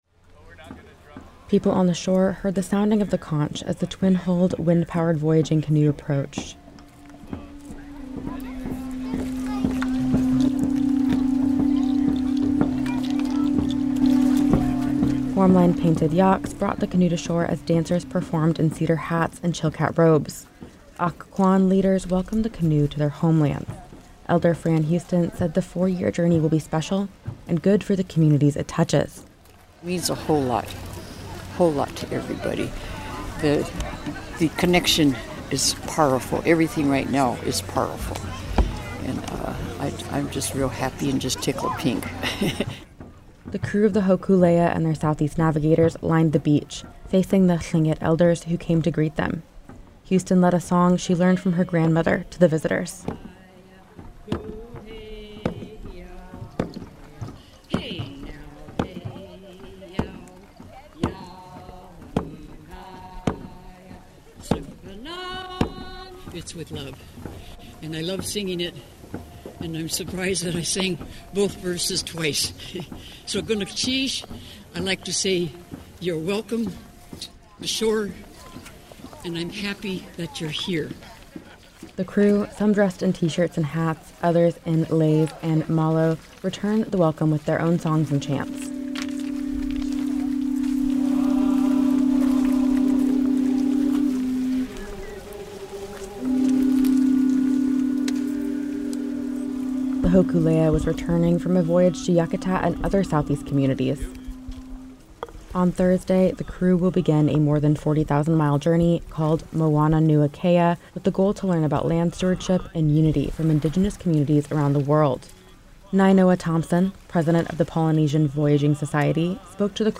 A thousand people gathered at Auke Bay in Juneau on Saturday to welcome the Hōkūle‘a and her crew to Juneau.
People on the shore heard Pu Kani — the sounding of the conch — as the twin-hulled, wind-powered voyaging canoe approached.
The crew — some dressed in t-shirts and hats, others in leis and malo — returned the welcome with their own songs and chants.